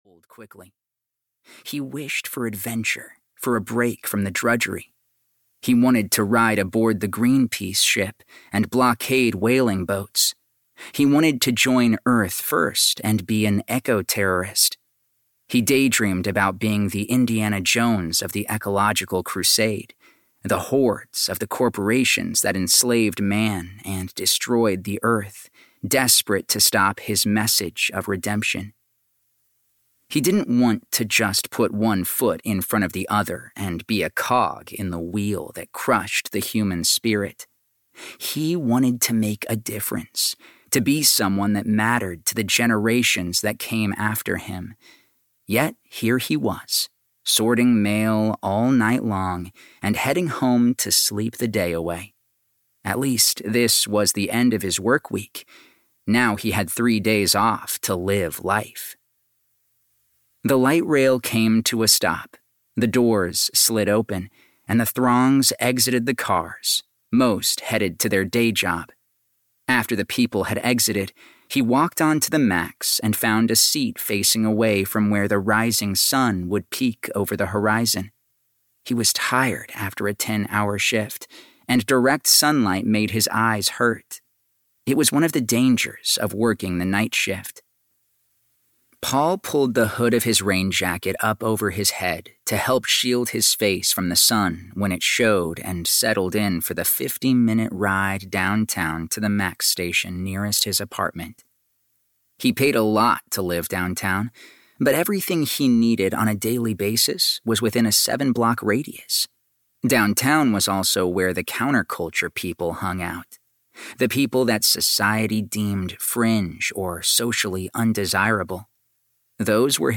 Accidental Wizard (EN) audiokniha
Ukázka z knihy